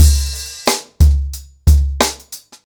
TheStakeHouse-90BPM.21.wav